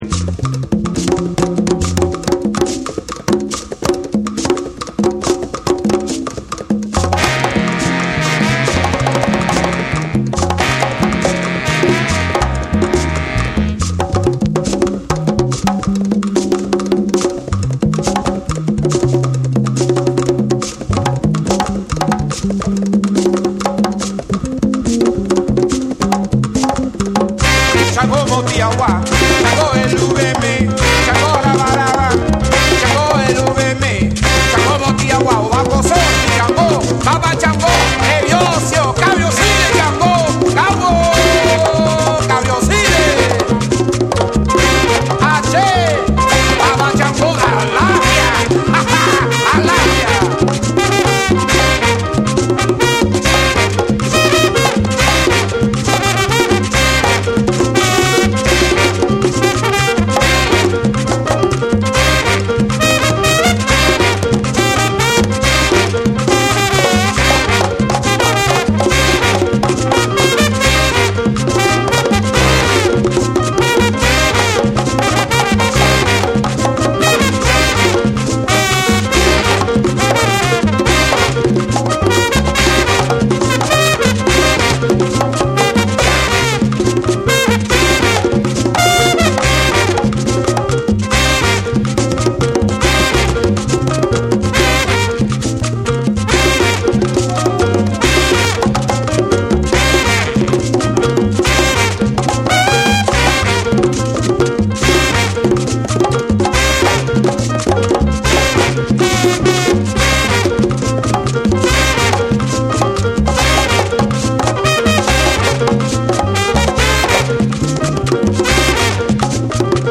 躍動感あふれるパーカッションのリズムが炸裂する
WORLD